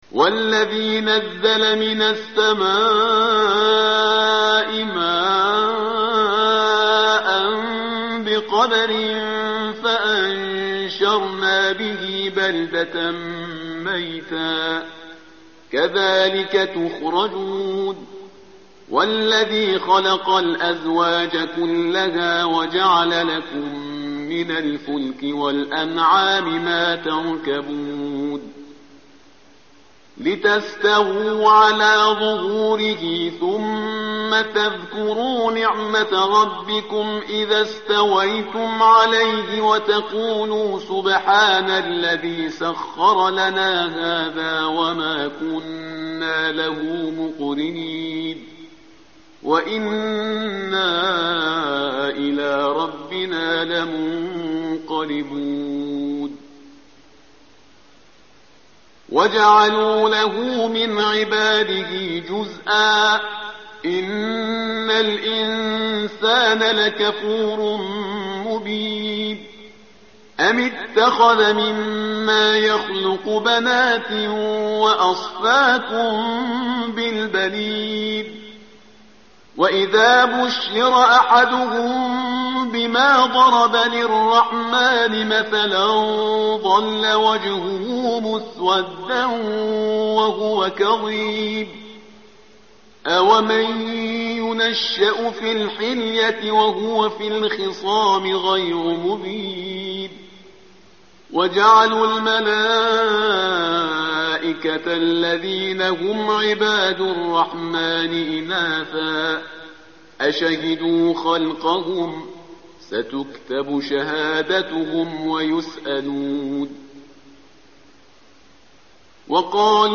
tartil_parhizgar_page_490.mp3